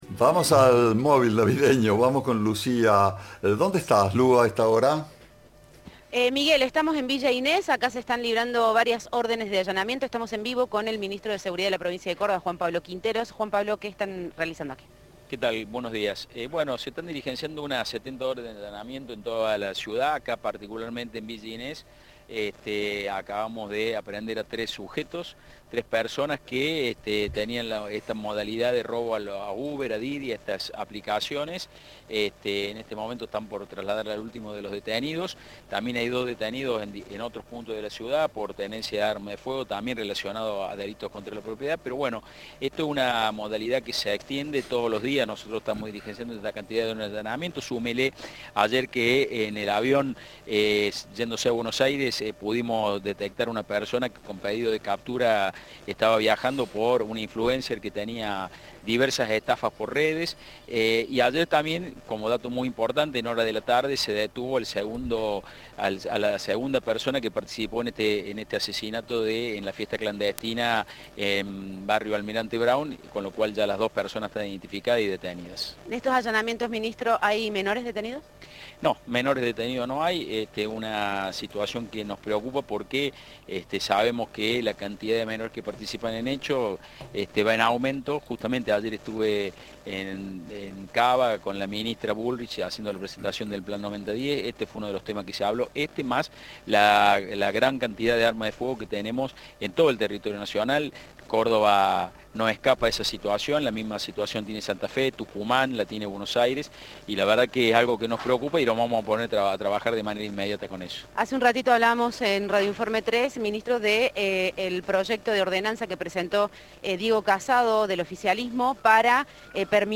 El ministro de Seguridad de Córdoba se refirió en diálogo con Cadena 3 al proyecto presentado por el concejal Diego Casado para regular la tenencia de armas menos letales.
Entrevista